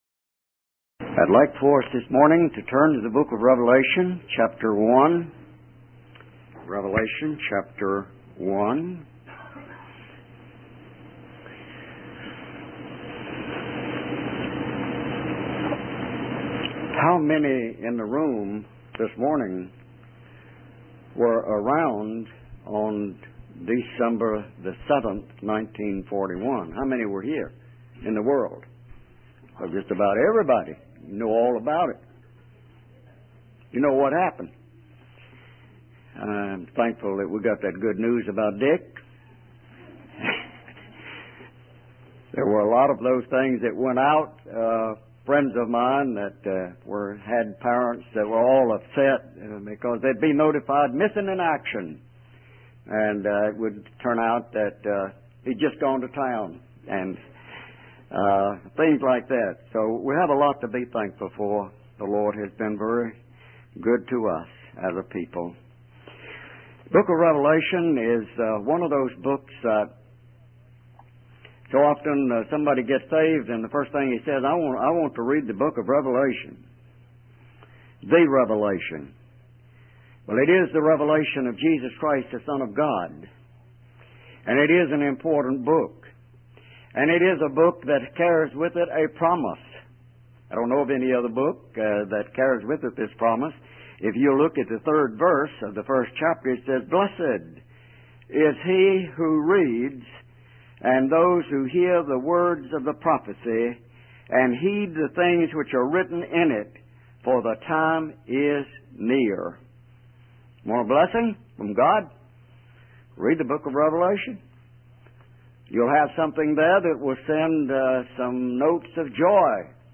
In this sermon, the preacher focuses on the book of Revelation and its significance for believers.